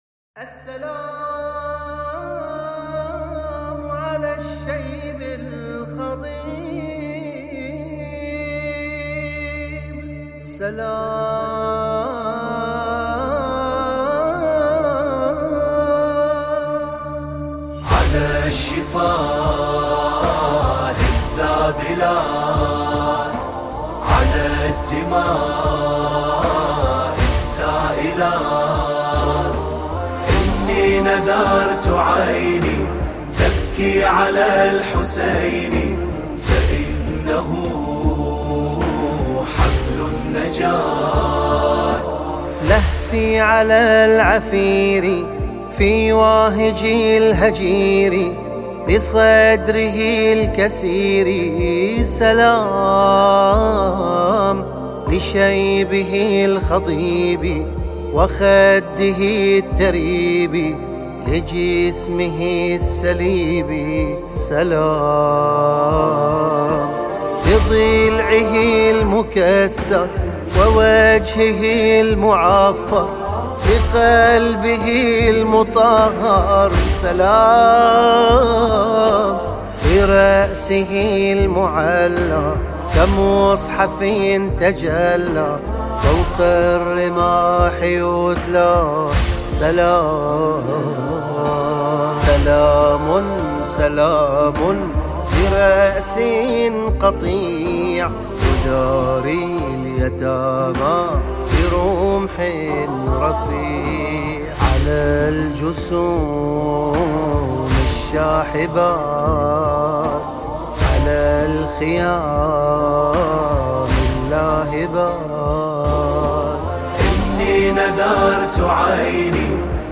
نماهنگ دلنشین عربی